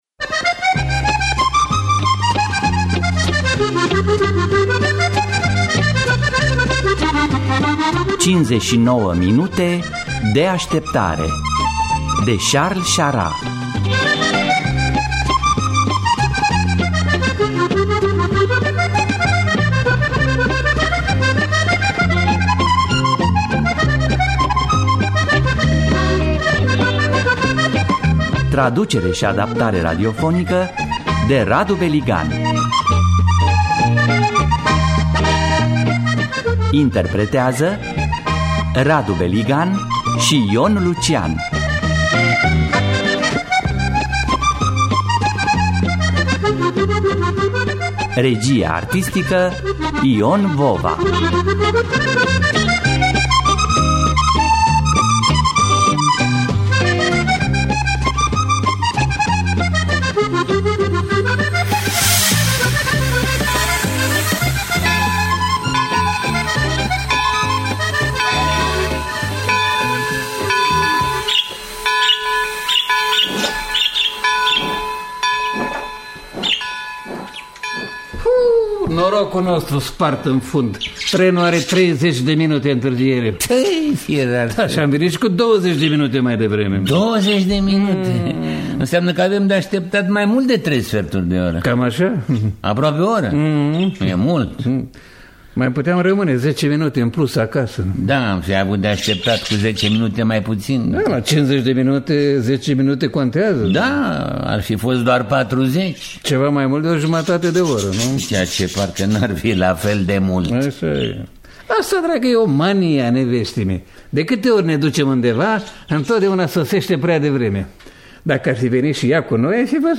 Traducerea și adaptarea radiofonică de Radu Beligan.